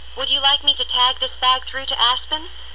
(加連線者為連音，加網底者不需唸出聲或音很弱。)